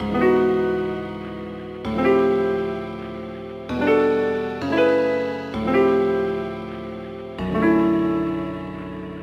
描述：2注意riff 104bpm
Tag: 民族 原生 迪吉里杜管